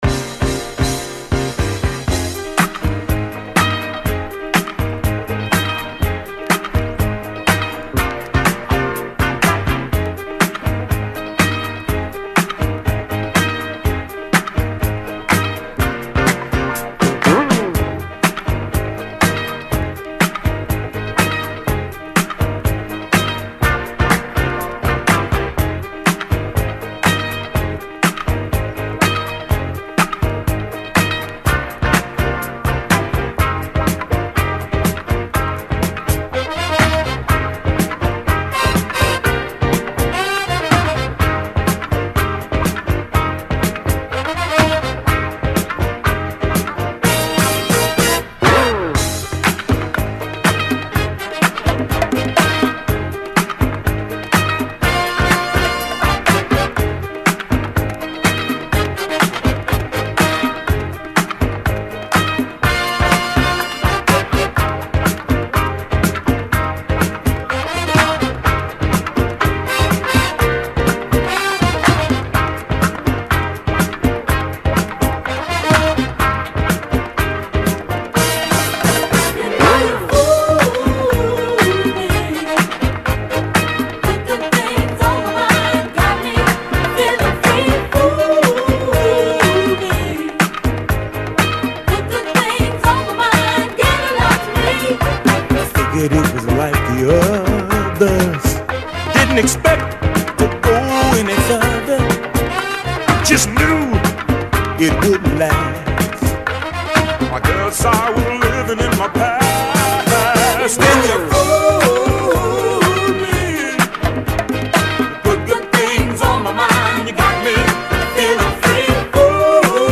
Жанр: Electronic, Funk / Soul